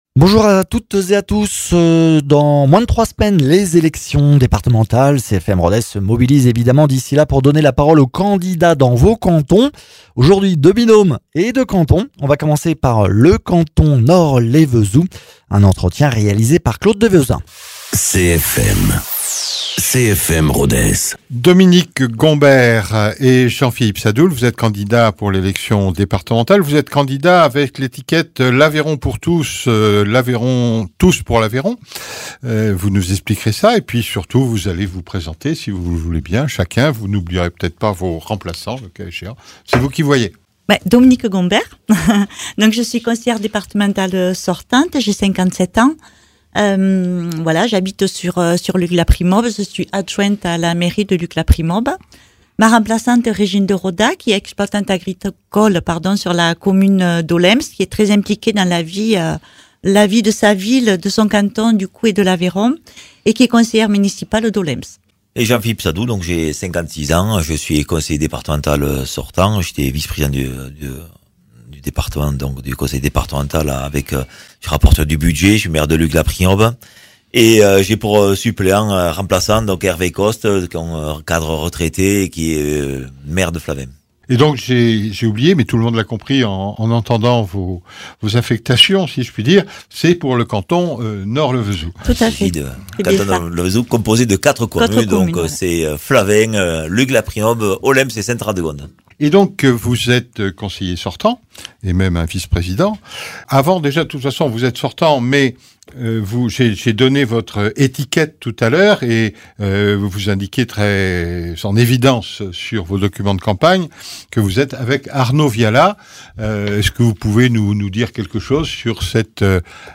Jusqu’au 16 juin, CFM Rodez invite les candidats des cantons de Rodez 1, Rodez 2, Rodez Onet, Nord Lévezou, du canton du Vallon et du canton du Causse Comtal en vue des départementales des 20 et 27 juin.